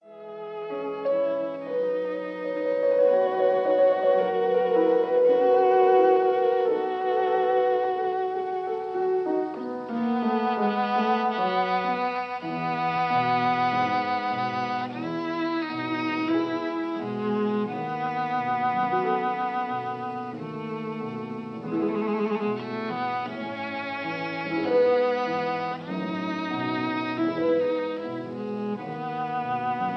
Adagio cantabile - Allegro vivace
in A major
piano